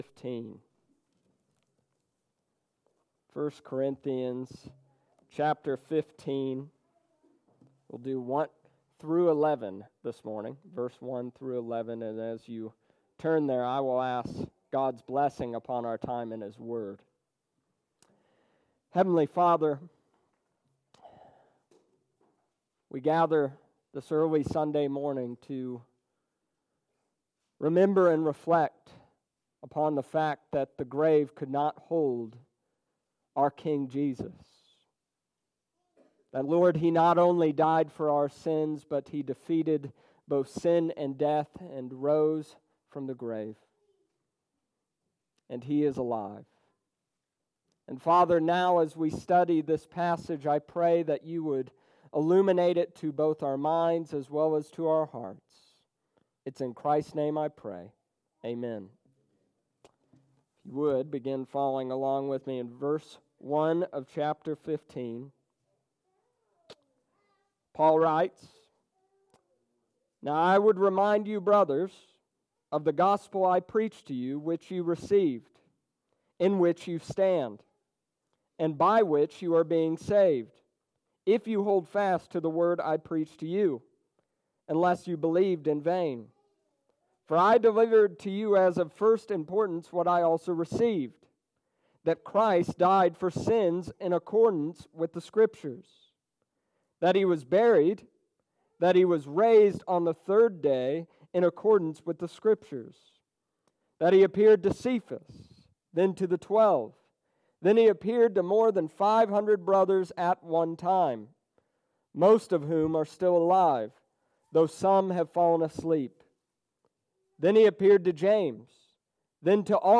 The Gospel We Believe and Preach 1 Corinthians chapter 15 verses 1-11 Easter Sunrise Service April 21st 2019